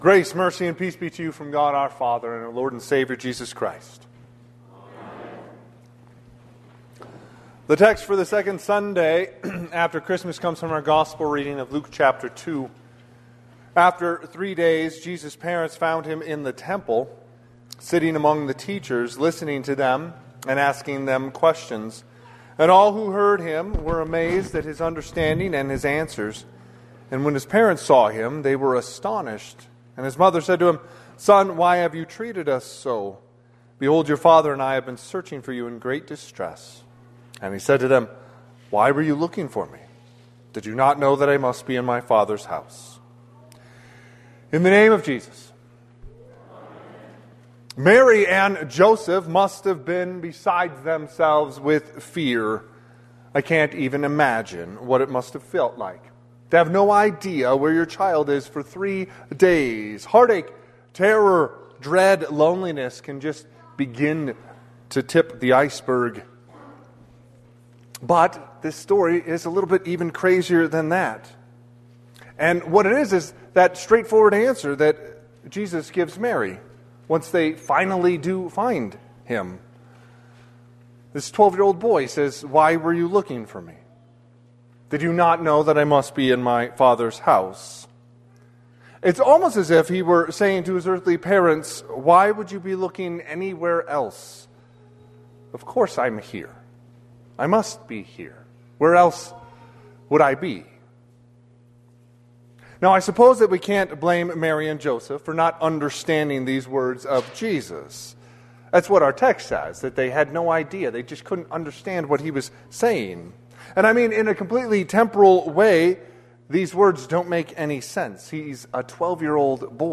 Sermon – 1/4/2026